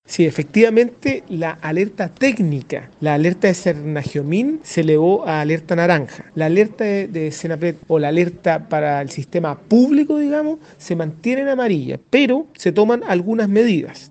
El delegado presidencial de La Araucanía, José Montalva, confirmó el cambio de alerta técnica tras el cogrid regional, aclarando que es la “alerta de Sernageomin (la que) se elevó a naranja”.